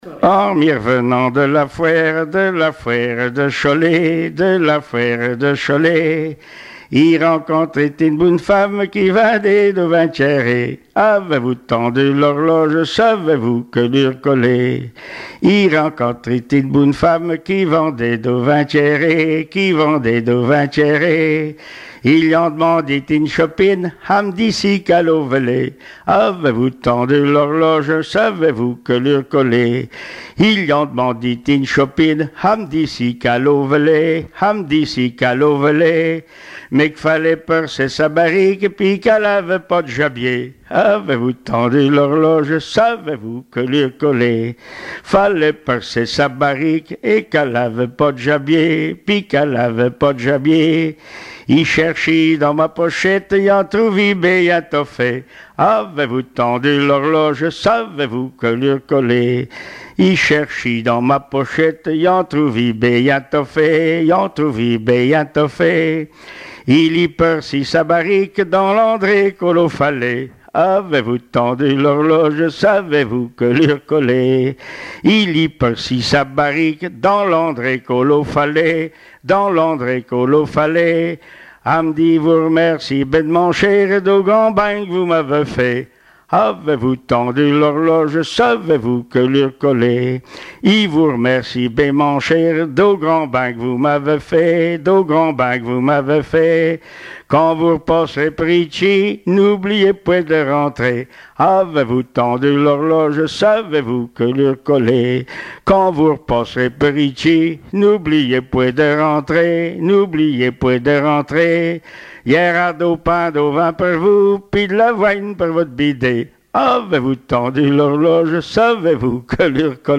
Genre laisse
Témoignages et chansons traditionnelles et populaires
Pièce musicale inédite